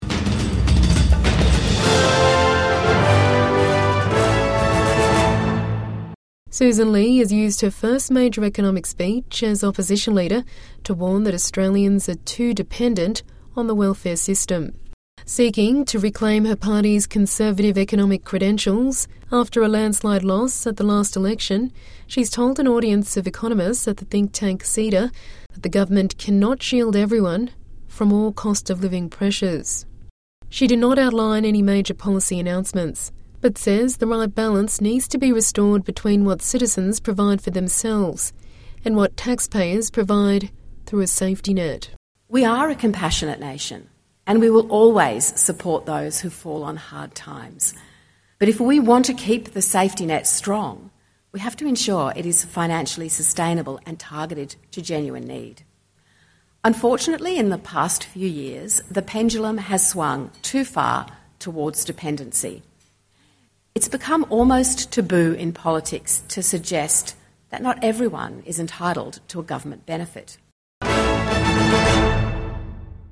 Opposition leader Sussan Ley uses speech to economists to discuss welfare payments